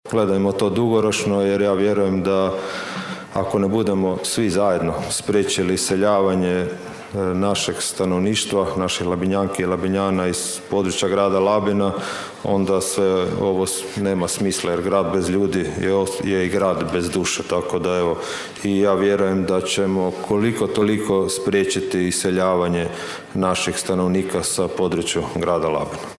Poruka gradonačelnika Donalda Blaškovića
ton – Donald Blašković), poručuje gradonačelnik Grada Labina Donald Blašković.